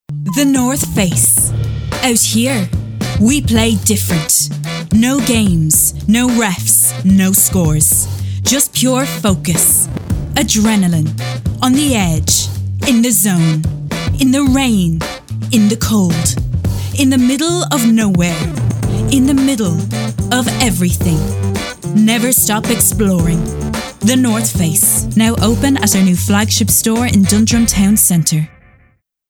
Cool, natural and light.
Voice samples